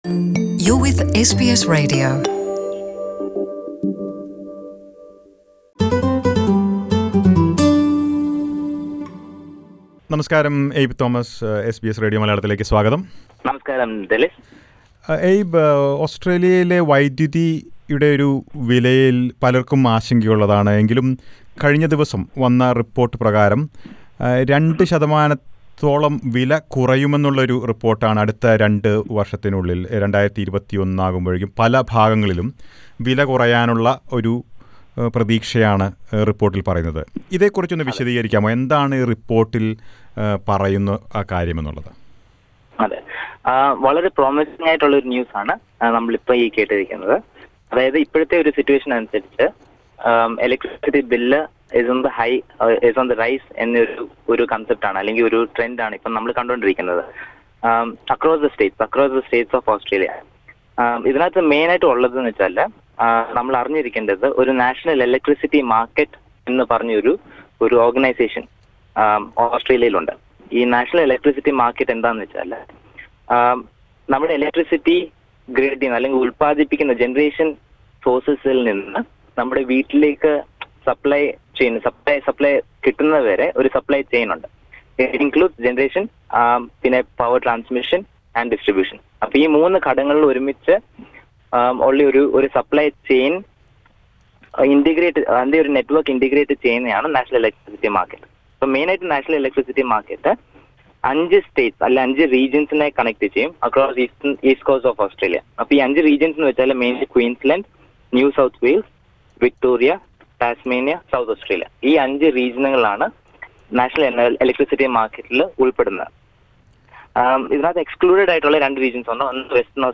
Major renewable energy projects in different parts of Australia is expected to drive energy expenses down in the next two years. Listen to an interview about what changes can be expected and also some tips on how to save on your energy bill.